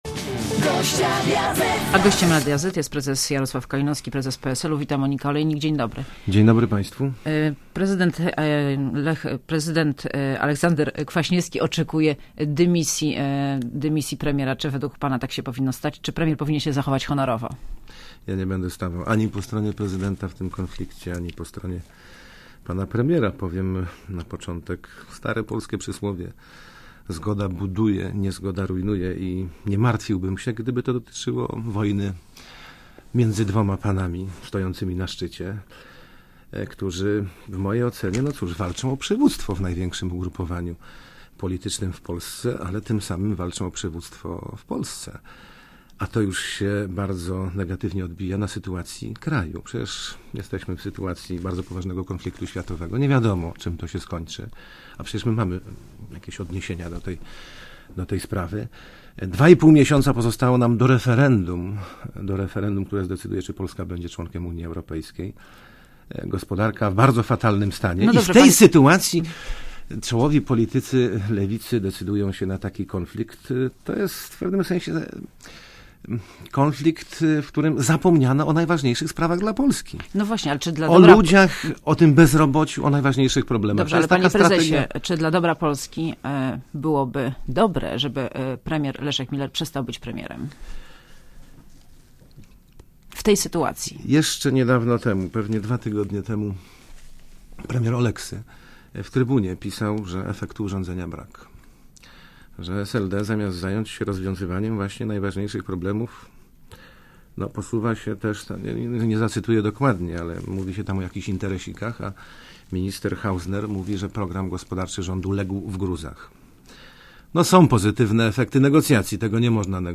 Monika Olejnik rozmawia z Jarosławem Kalinowskim - szefem PSL